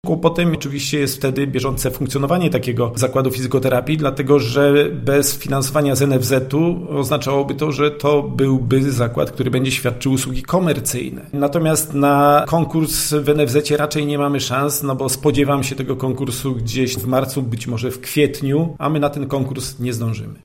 ’- Bardzo nam się ten pomysł podoba, ale mamy poważne problemy z jego realizacją – mówi Krzysztof Jarosz, burmistrz Gozdnicy.